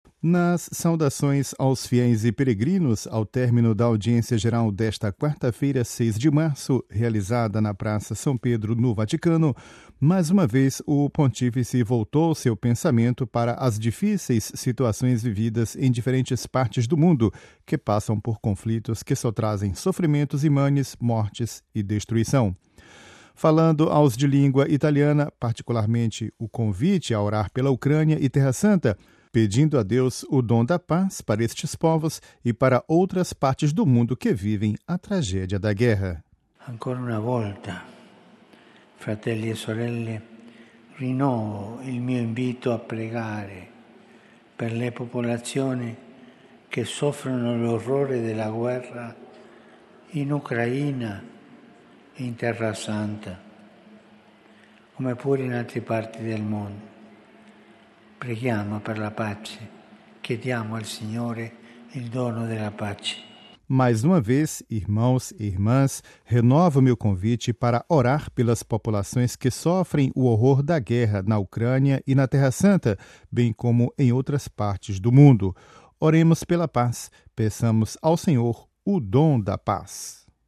Ao término da audiência geral desta quarta-feira, na Praça São Pedro, o Santo Padre mais uma vez pediu orações pelas populações que sofrem o horror da guerra
Ouça com a voz do Papa Francisco e compartilhe